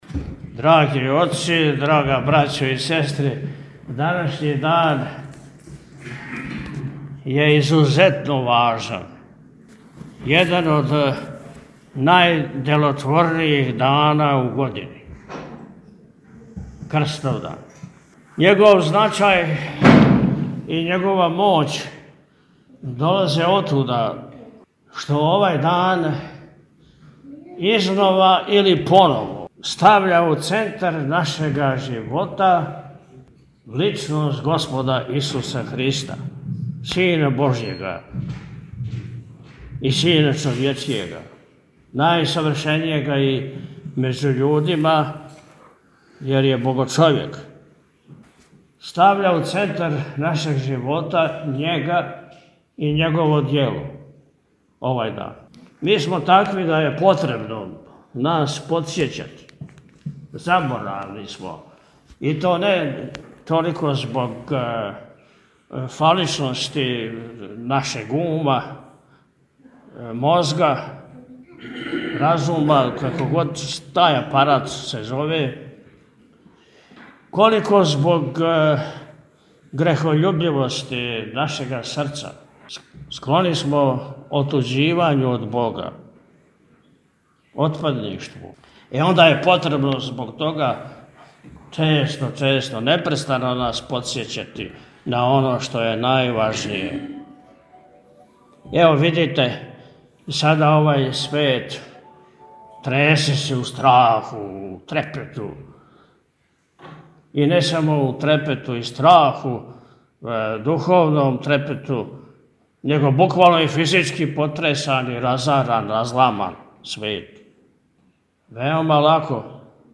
Поводом Празника Воздвижења Часног Крста, Његово Високопреосвештенство Архиепископ и Митрополит милешевски г. Атанасије служио је најпре свечано бденије у навечерје Празника, 26. септембра 2025. године, и Божанску Литургију у цркви Светог Спаса манастира Милешеве сутрадан на Празник.